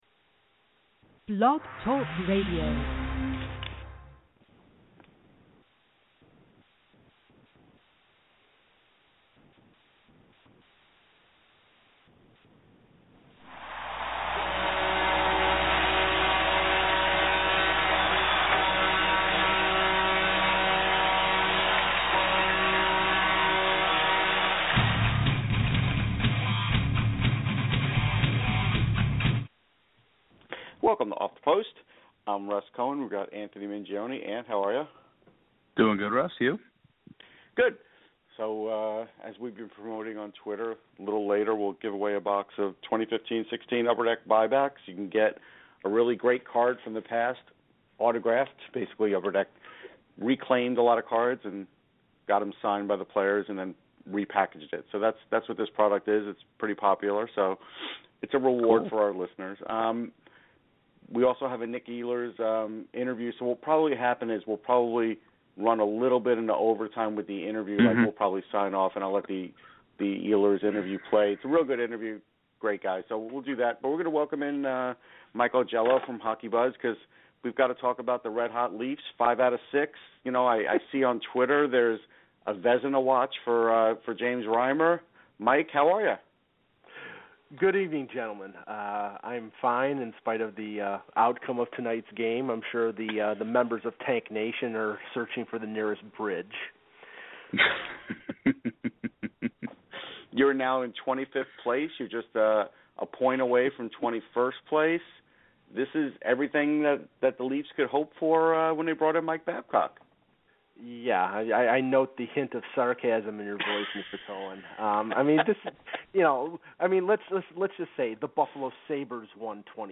We'll be talking Leafs, Marlies and a few other current news items. This weeks interview will be Nikolai Ehlers of the Winnipeg Jets.